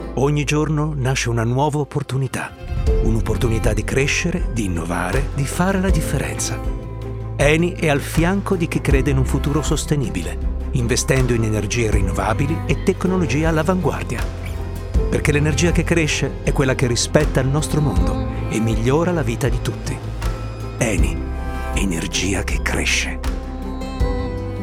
European, Italian, Male, 30s-50s